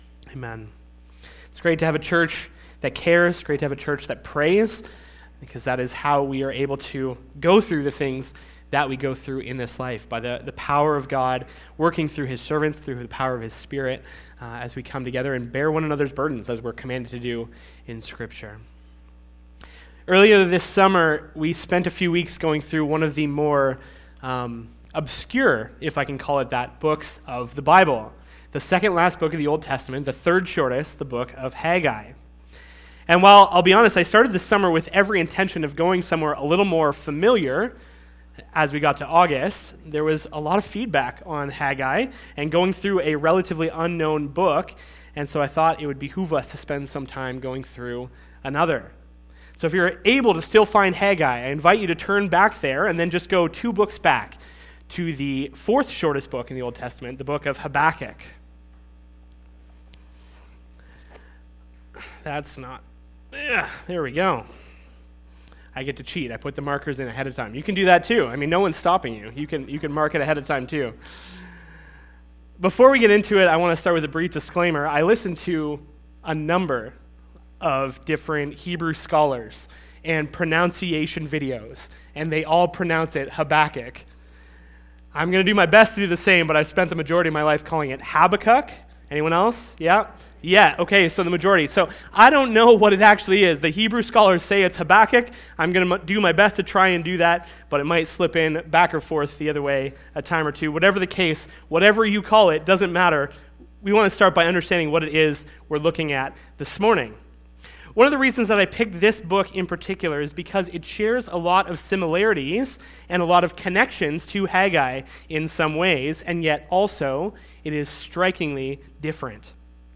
Sermon Audio and Video How Long?